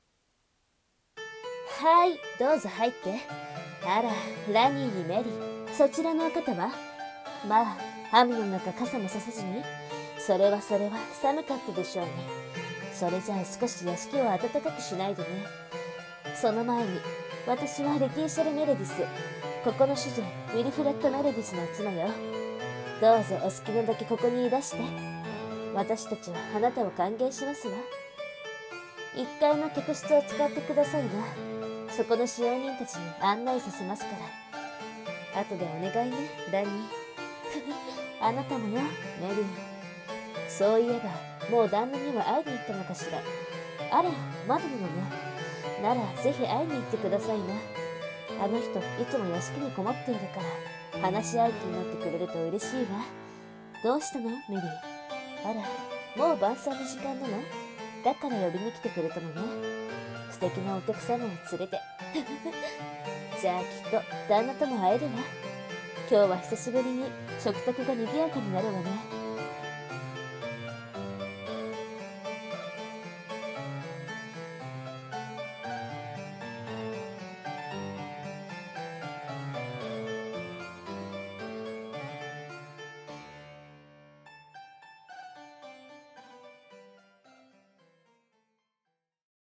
声劇 Columbine